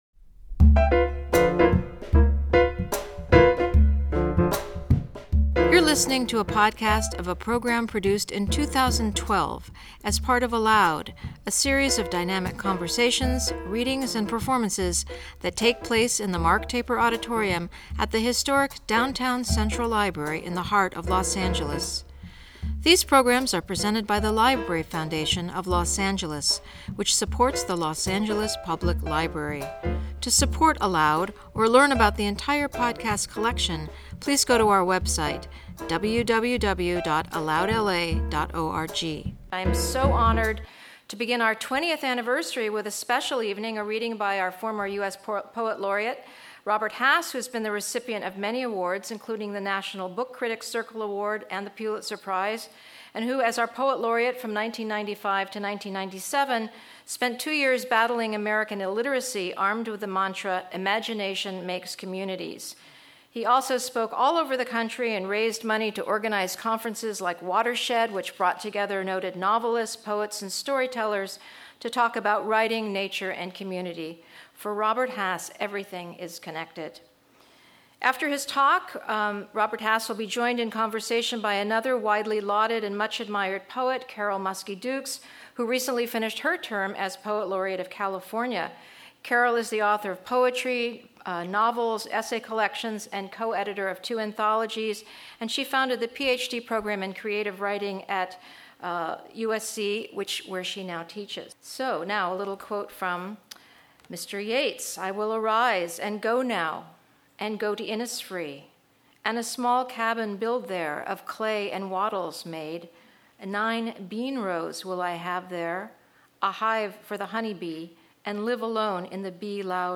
In conversation with Carol Muske-Dukes